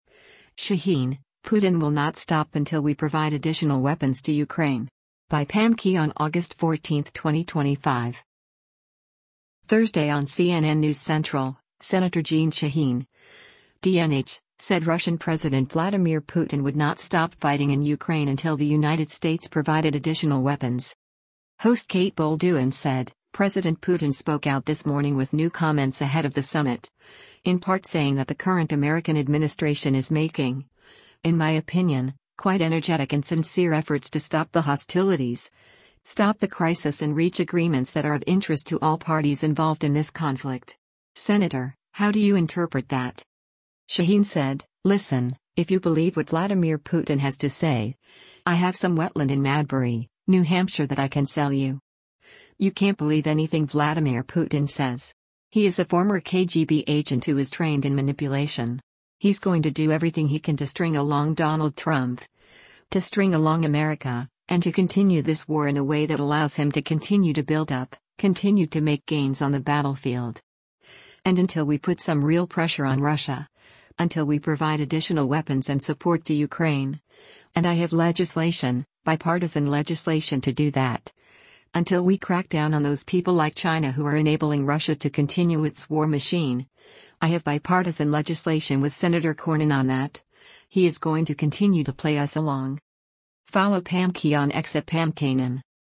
Thursday on CNN “News Central,” Sen. Jeanne Shaheen (D-NH) said Russian President Vladimir Putin would not stop fighting in Ukraine until the United States provided “additional weapons.”